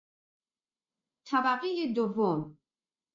جلوه های صوتی
برچسب: دانلود آهنگ های افکت صوتی اشیاء دانلود آلبوم صدای اعلام طبقات آسانسور از افکت صوتی اشیاء